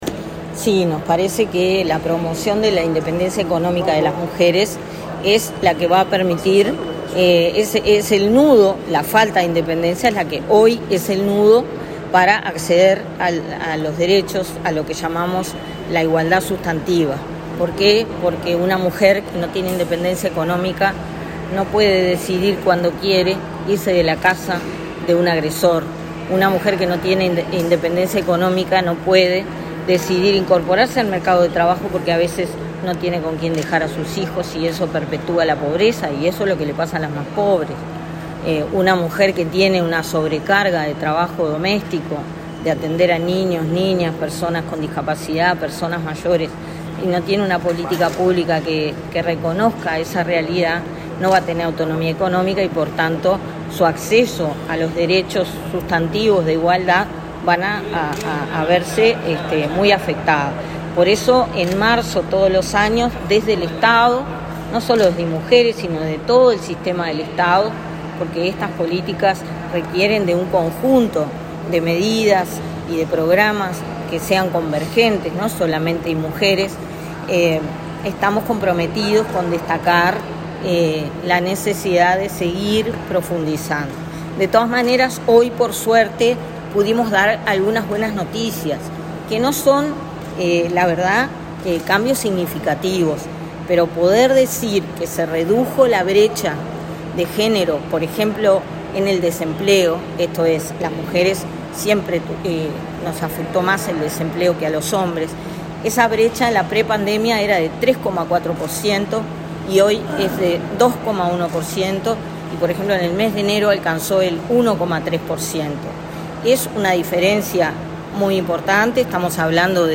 Declaraciones de la directora del Inmujeres, Mónica Bottero
La directora del Instituto Nacional de las Mujeres (Inmujeres), Mónica Bottero, dialogó con la prensa, luego de participar en el acto por el Día